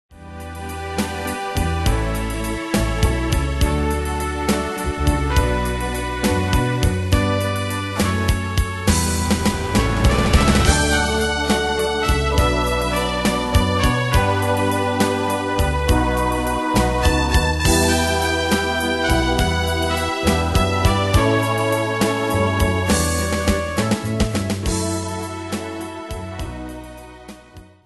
Style: Retro Année/Year: 1973 Tempo: 137 Durée/Time: 3.31
Danse/Dance: Ballade Cat Id.
Pro Backing Tracks